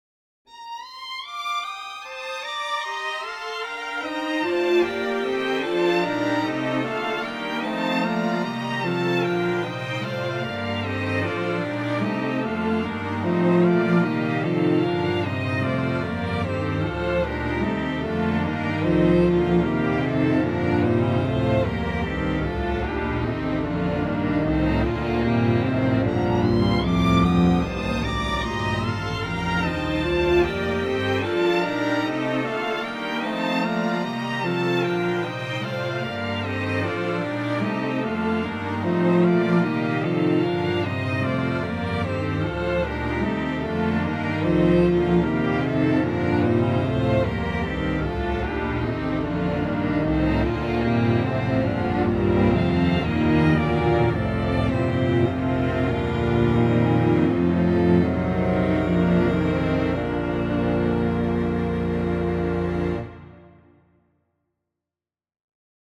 Perpetual Canon on Every Diatonic Interval for String Octet in B-flat major.